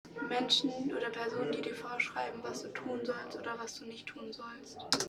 MS Wissenschaft @ Diverse Häfen